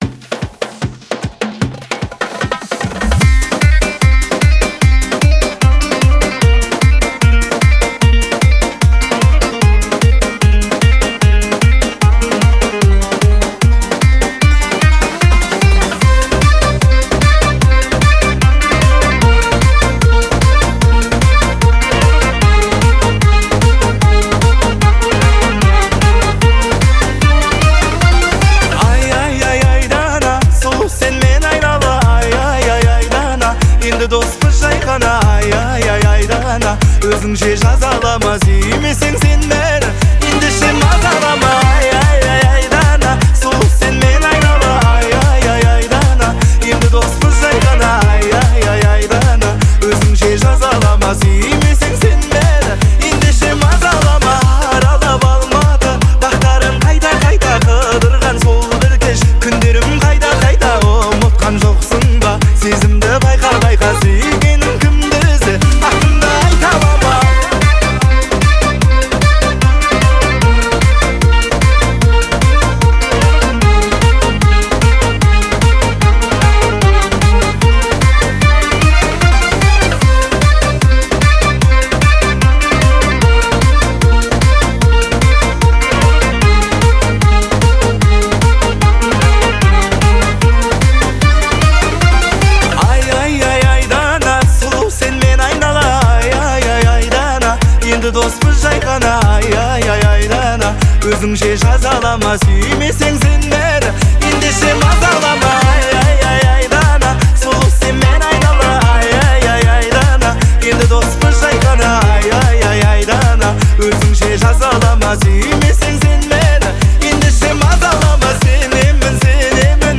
Звучание выделяется мелодичными инструментами